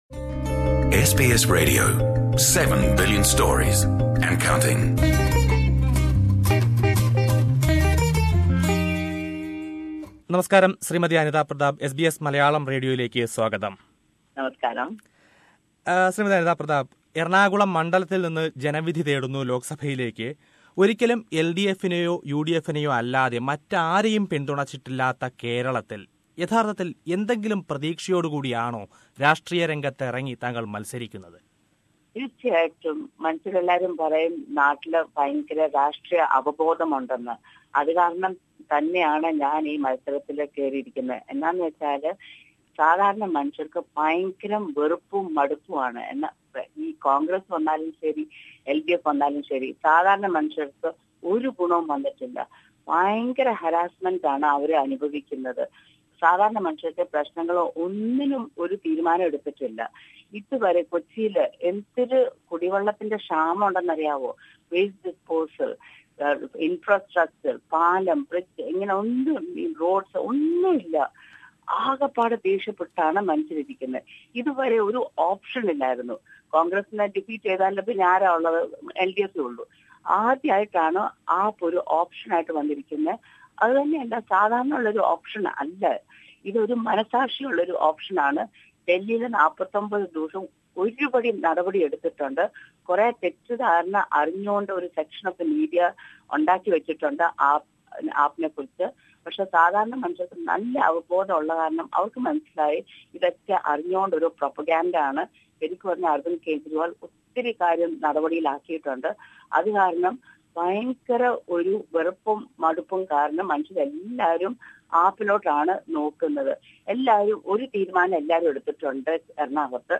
As a representative of the Aam Admi Party, famous journalist and writer Anita Pratap - who is contesting in the Eranakulam constituency - talked to SBS Malayalam Radio. Let us listen how she answers to the questions and criticisms raised against AAP…